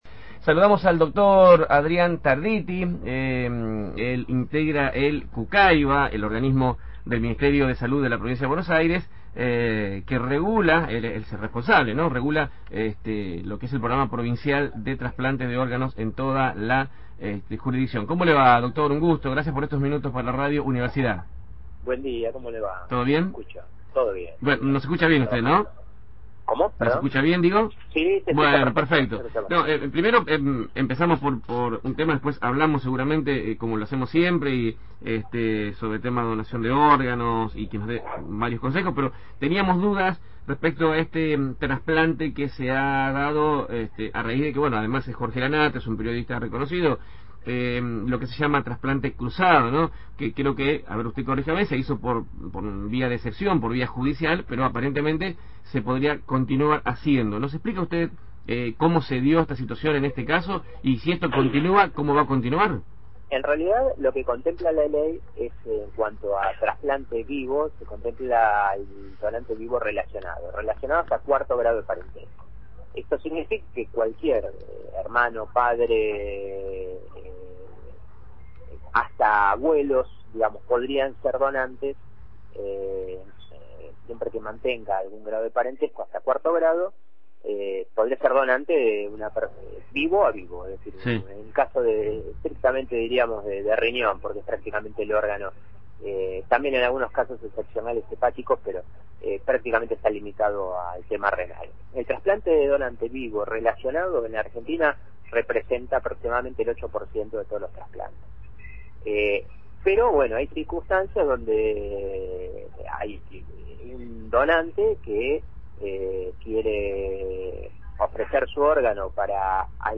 El presidente del Centro Único Coordinador de Ablación e Implante Provincia de Buenos Aires (CUCAIBA), Adrián Tarditti, dialogó